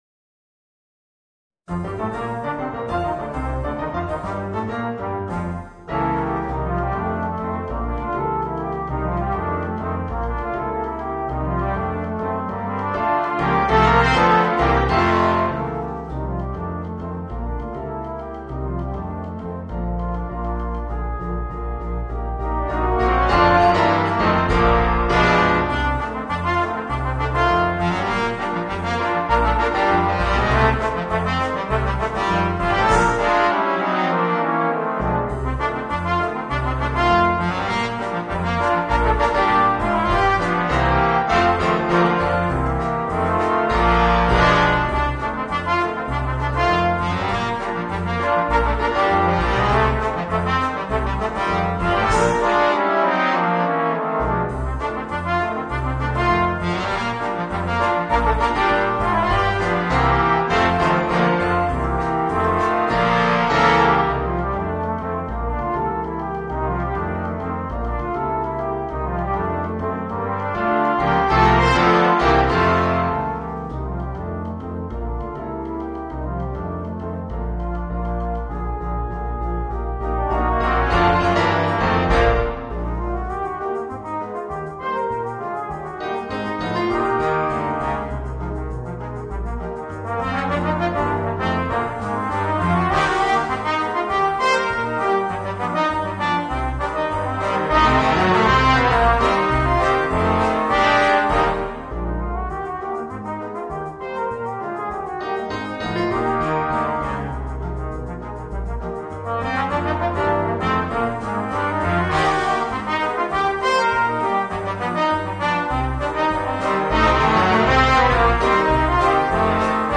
Voicing: 4 Trombones and Piano